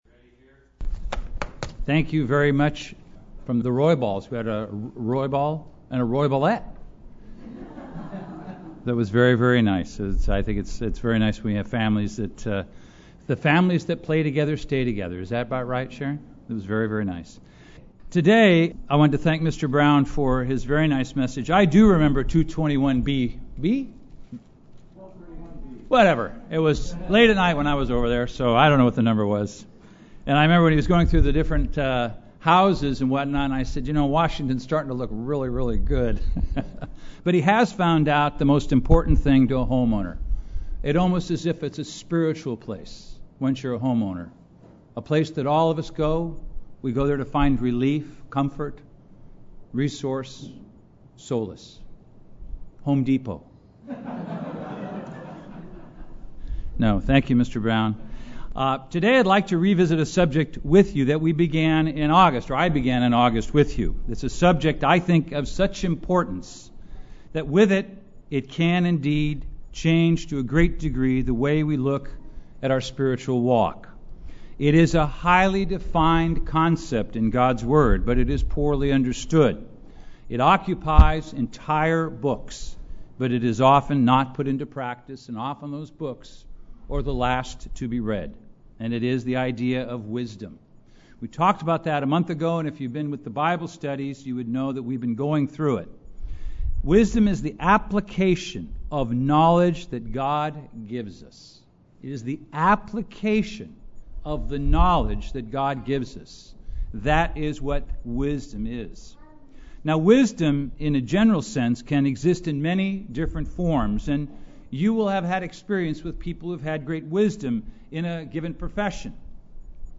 Based on the book of Ecclesiastes, this sermon examines wisdom: the proper application of the knowledge God gives us.
Given in Los Angeles, CA